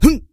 XS格挡3.wav
XS格挡3.wav 0:00.00 0:00.34 XS格挡3.wav WAV · 29 KB · 單聲道 (1ch) 下载文件 本站所有音效均采用 CC0 授权 ，可免费用于商业与个人项目，无需署名。